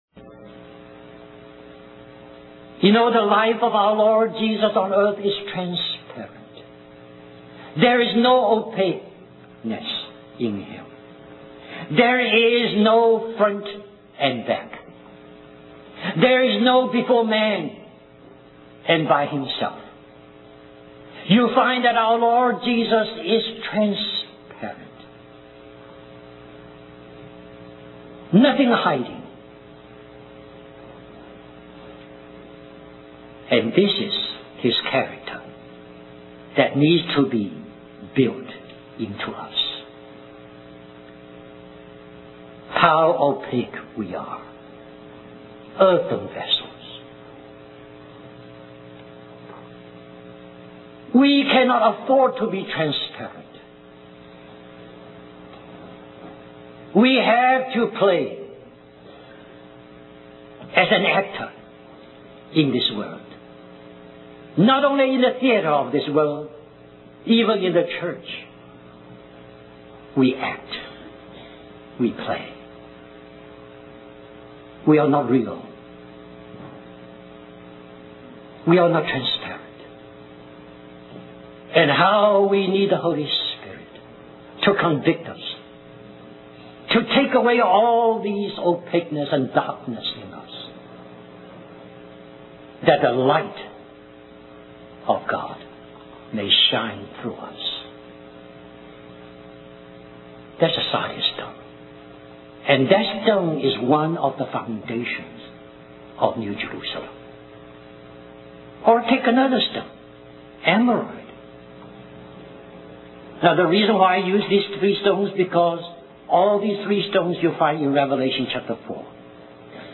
1990 Christian Family Conference Stream or download mp3 Summary This message is a continuation of the message found here .